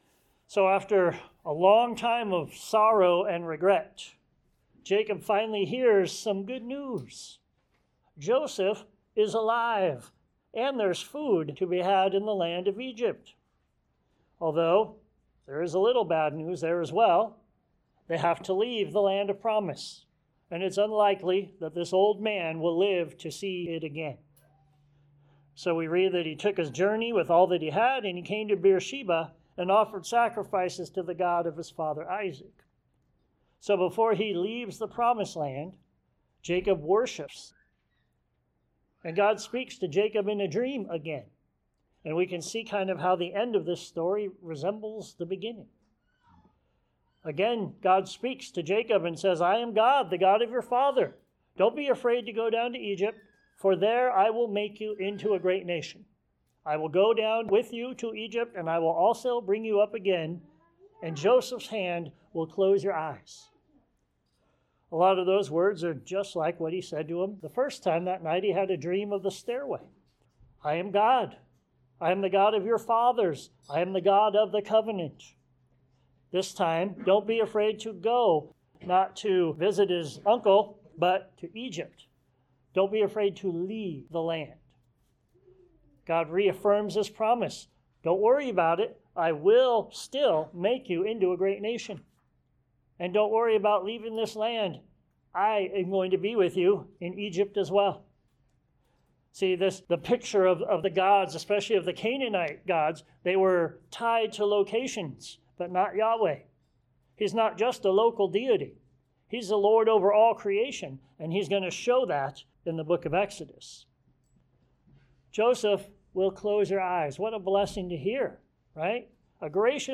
Genesis 46-47 Our Time in Egypt Sermons Share this: Share on X (Opens in new window) X Share on Facebook (Opens in new window) Facebook Like Loading...